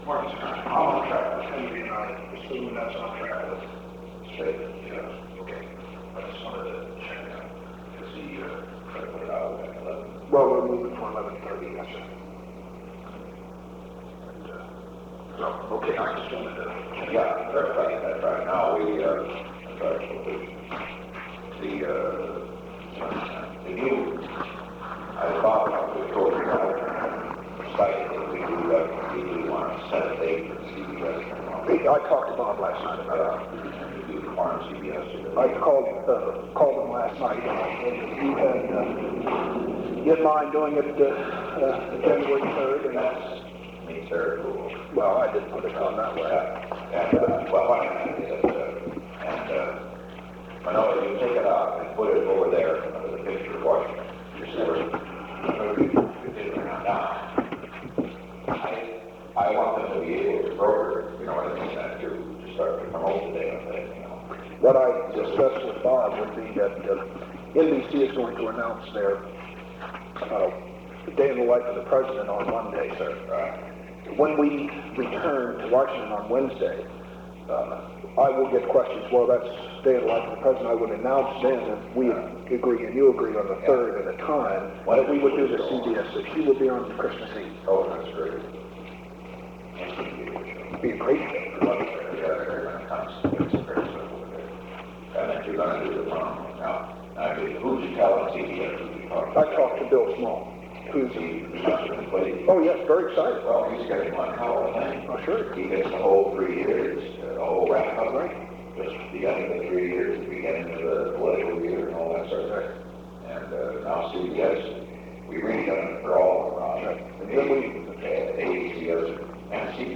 Secret White House Tapes
Conversation No. 637-9
Location: Oval Office
The President met with Ronald L. Ziegler.